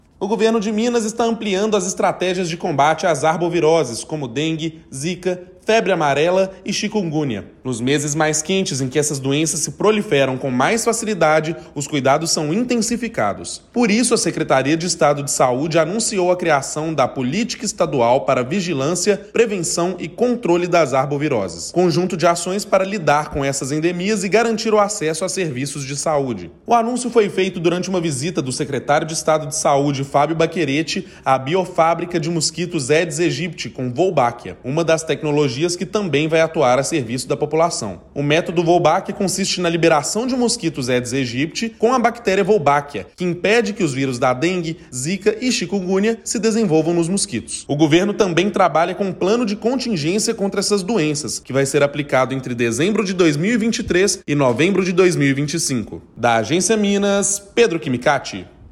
Ações foram apresentadas durante visita à biofábrica Wolbachia nesta segunda-feira (13/11). Ouça matéria de rádio.